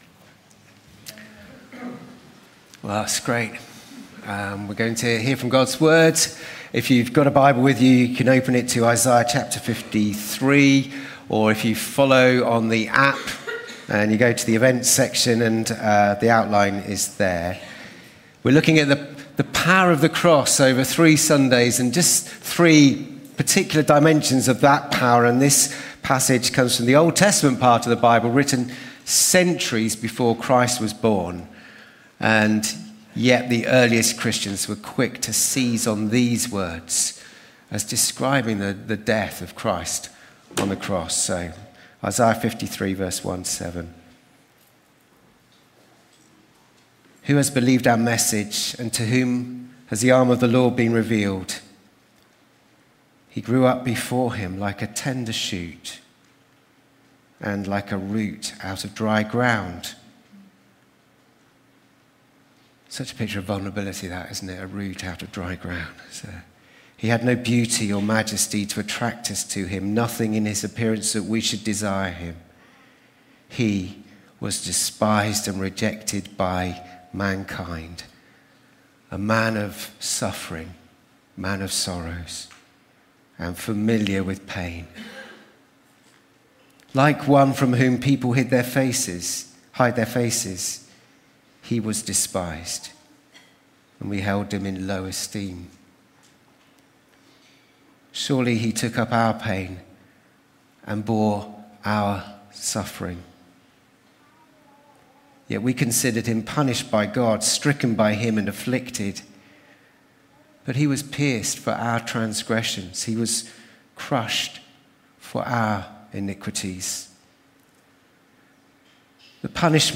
The Power of the Cross | Sermon Series | Christchurch Baptist, Welwyn Garden City